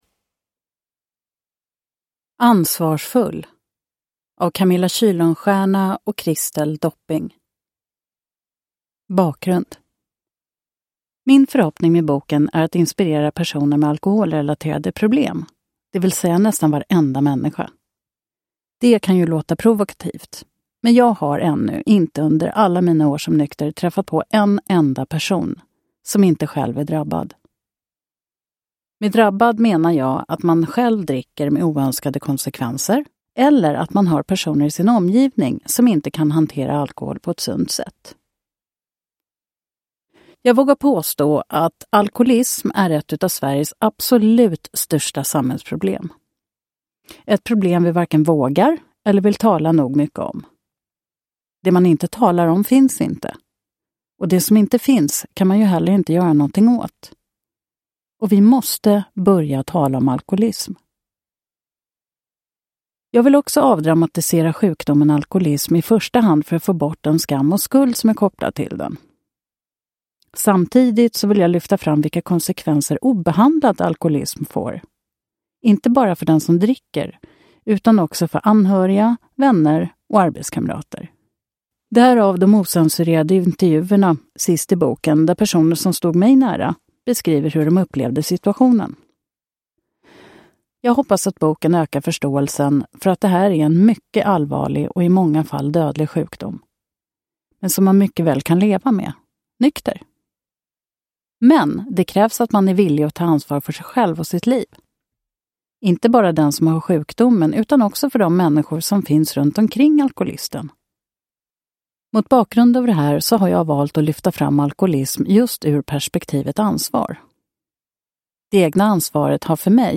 Ansvarsfull – Ljudbok – Laddas ner